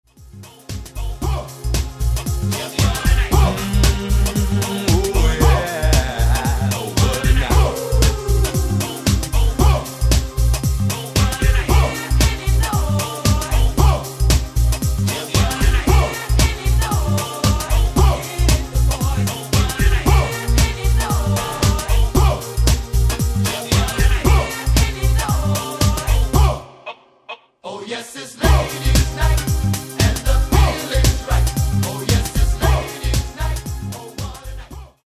Genere:   Disco | Soul | Funk